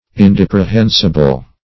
Search Result for " indeprehensible" : The Collaborative International Dictionary of English v.0.48: Indeprehensible \In*dep`re*hen"si*ble\, a. [L. indeprehensibilis.
indeprehensible.mp3